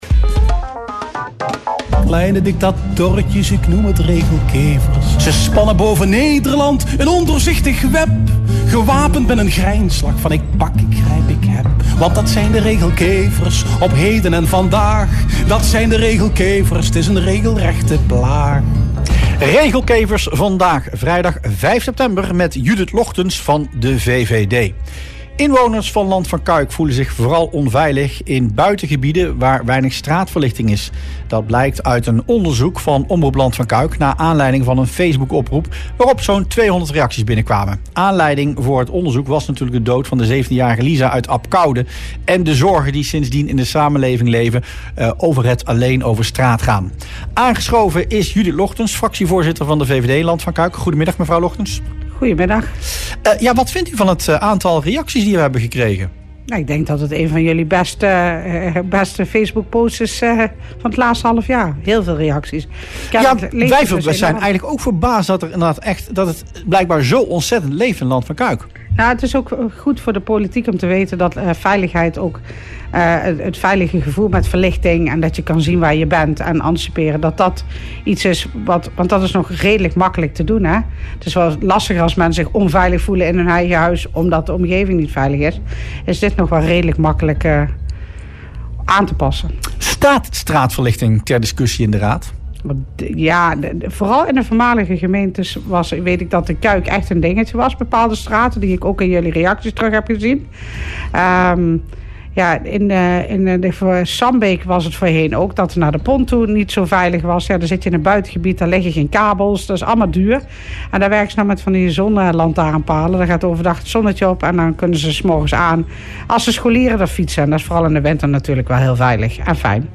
MILL – De VVD-fractie wil dat de gemeente meer werk maakt van straatverlichting in de buitengebieden. Volgens fractievoorzitter Judith Logtens voelen veel inwoners zich daar onveilig, vooral op routes die scholieren dagelijks gebruiken. Dat zei zij in radioprogramma Rustplaats Lokkant.